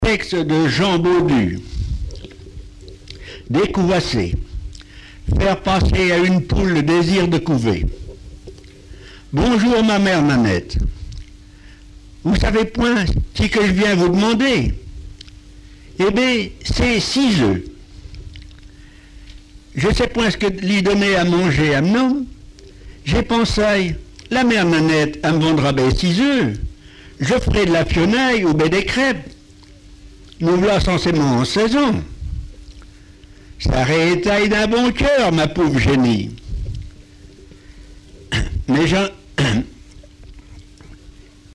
Genre récit
textes en patois et explications sur la prononciation